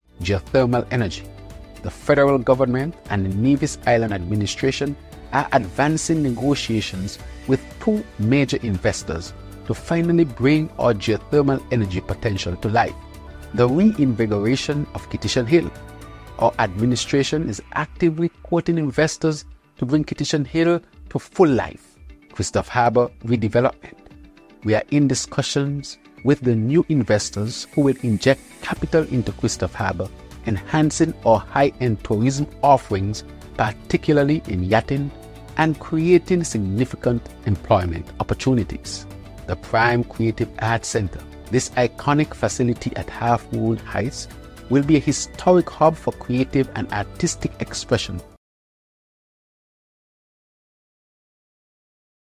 The Prime Minister gave information about the geothermal project and more: